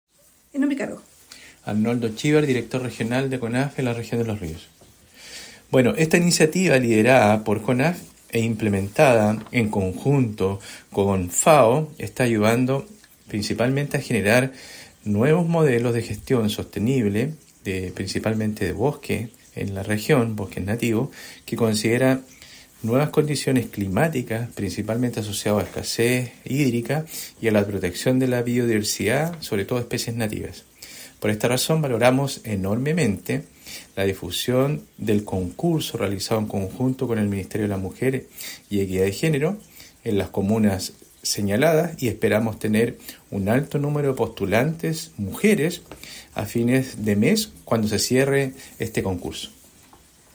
Cuna-DR-CONAF-Los-Rios.mp3